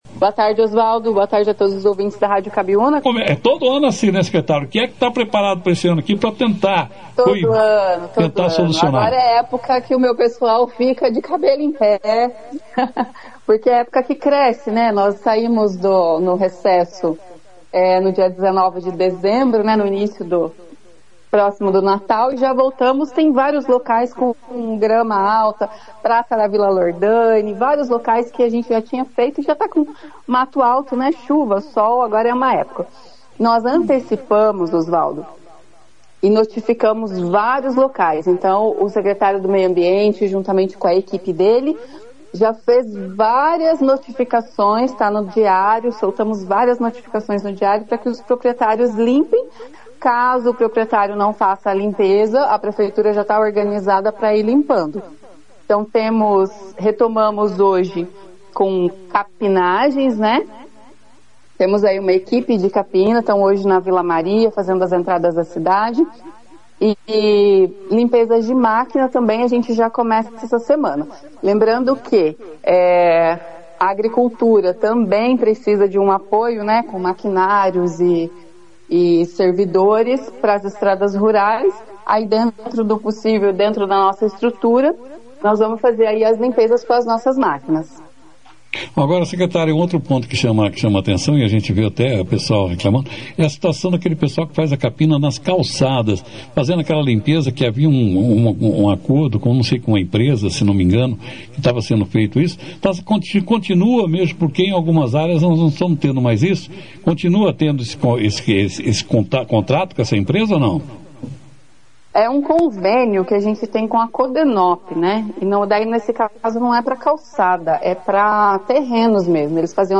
A secretária municipal de Administração de Bandeirantes, Cláudia Jans, (foto), participou da 2ª edição do Jornal Operação Cidade, desta segunda-feira, 05 de janeiro, quando falou sobre as inúmeras reclamações relacionadas a terrenos com mato alto no município, especialmente após o período de chuvas intensas.
Durante a entrevista, Cláudia Jans explicou o cronograma de roçagem que está sendo executado pela Prefeitura e destacou que os proprietários de terrenos estão sendo notificados para realizarem a limpeza dos imóveis.